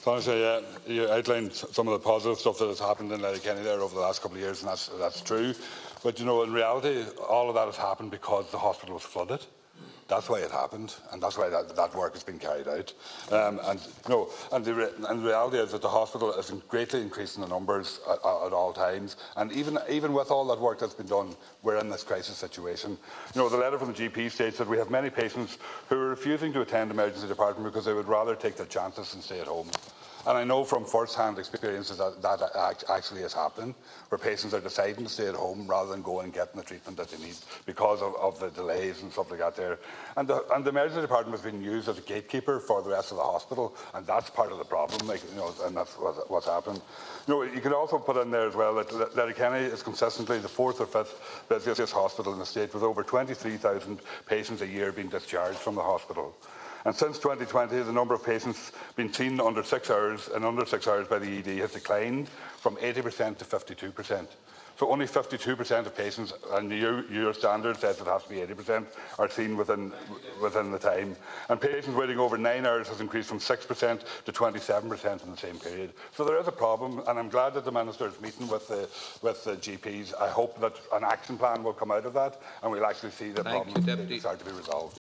Well, Deputy Pringle says the lengthy waiting times facing patients at the hospital is continuous evidence that urgent action is needed: